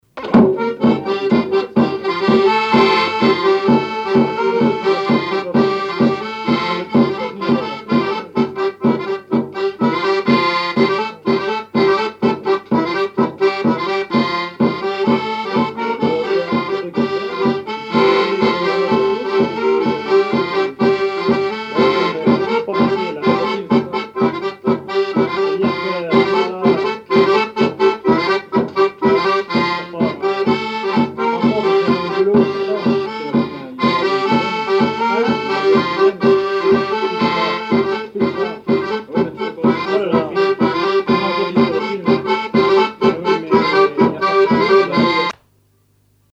Chants brefs - A danser
danse : gigouillette
répertoire à l'accordéon chromatique et grosse caisse
Pièce musicale inédite